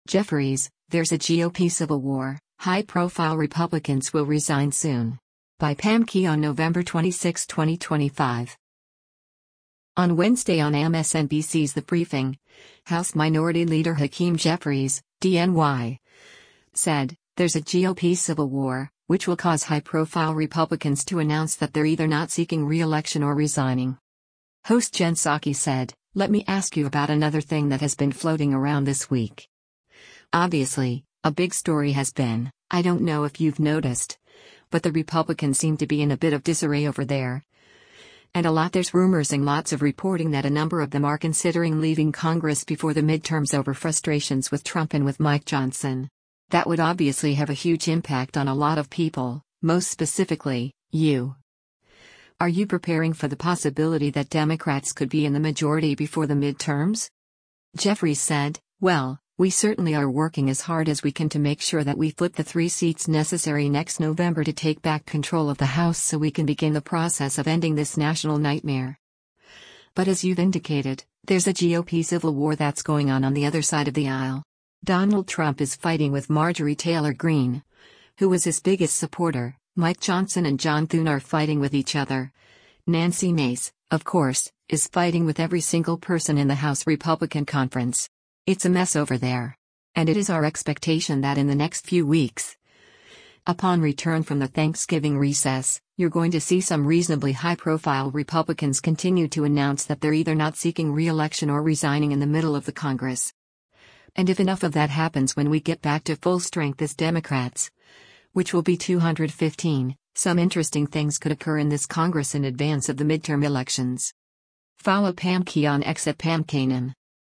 On Wednesday on MSNBC’s “The Briefing,” House Minority Leader Hakeem Jeffries (D-NY) said, “there’s a GOP civil war,” which will cause high-profile Republicans to announce that they’re either not seeking reelection or resigning.